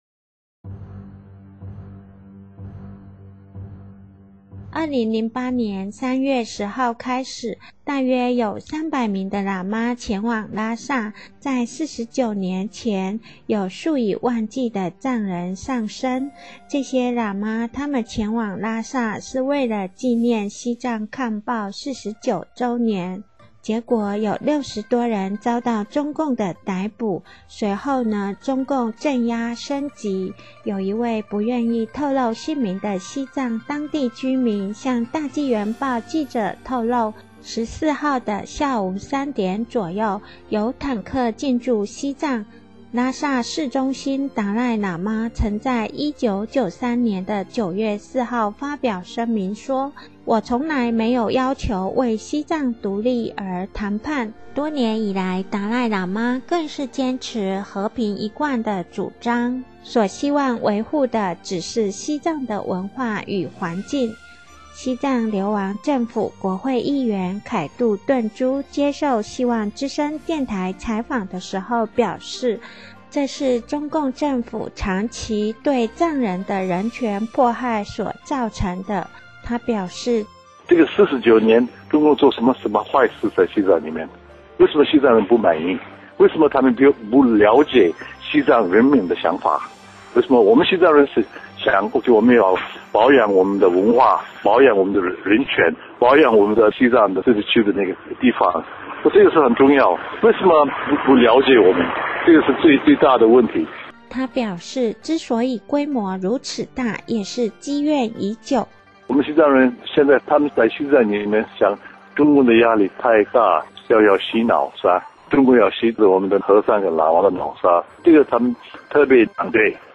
西藏流亡政府国会议员凯度顿珠接受本希望之声电台采访时表示，这是中共政府长期对藏人的人权迫害造成的。